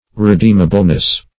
Meaning of redeemableness. redeemableness synonyms, pronunciation, spelling and more from Free Dictionary.